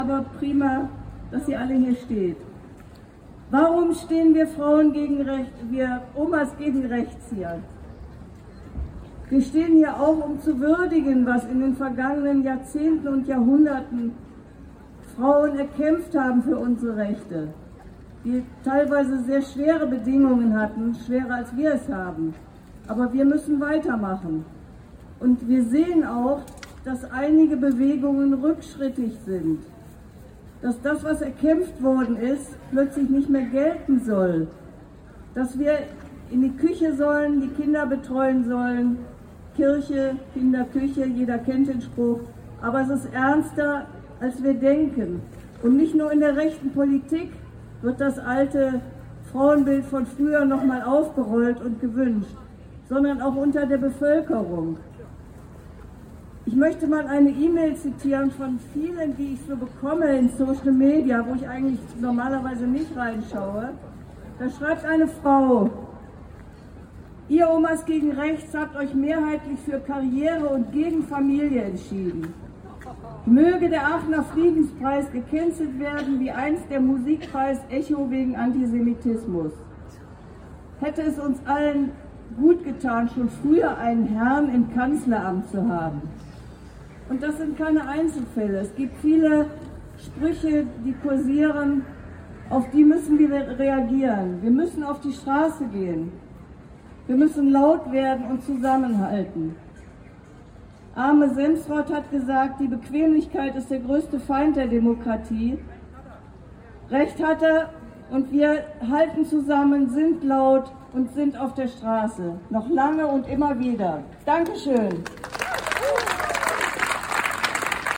Frauentag auf dem Rondeel
Wir hatten dazu eingeladen, auf dem Rondeel mit Musik, Ansprachen und Bücherflohmarkt zu feiern und baten darum, lila (Farbe des Feminismus), orange (Farbe der Gewalt gegen Frauen) oder bunt gekleidet zu kommen.